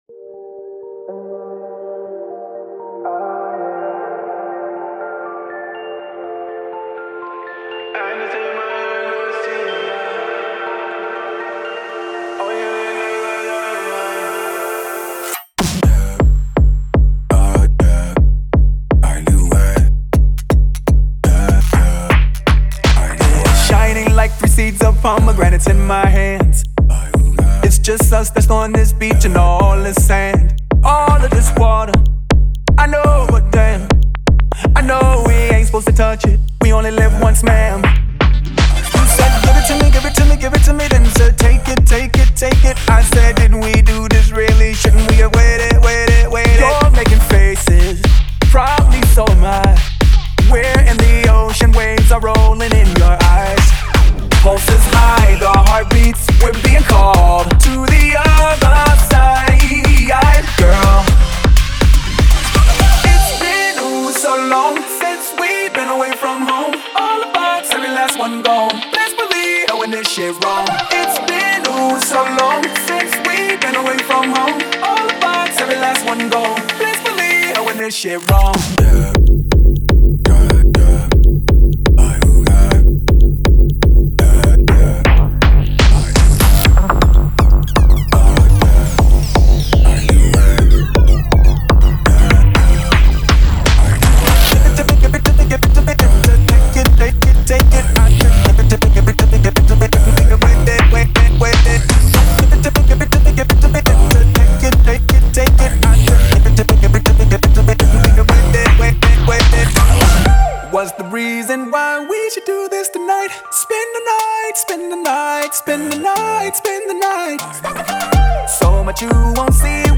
BPM122-122
Audio QualityPerfect (High Quality)
Electropop song for StepMania, ITGmania, Project Outfox
Full Length Song (not arcade length cut)